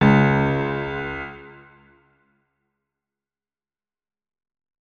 46265b6fcc Divergent / mods / Hideout Furniture / gamedata / sounds / interface / keyboard / piano / notes-13.ogg 55 KiB (Stored with Git LFS) Raw History